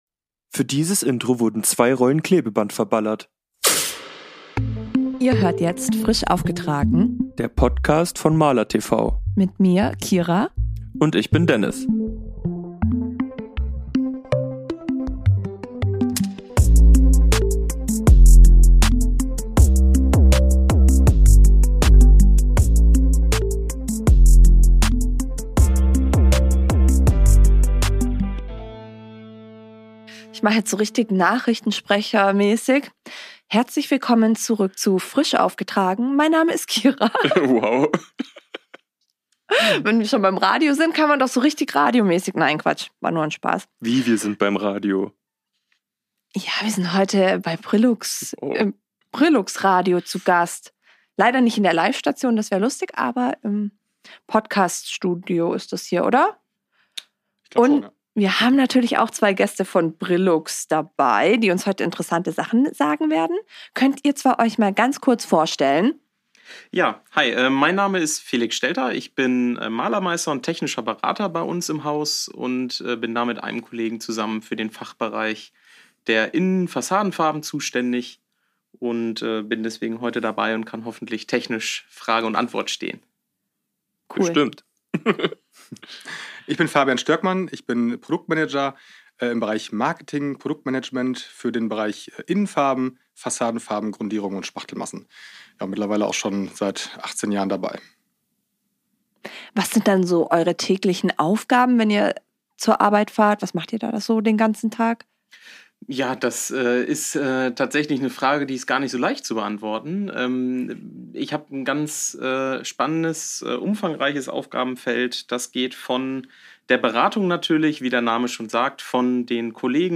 Dieses Mal waren wir bei Brillux in Münster zu Gast.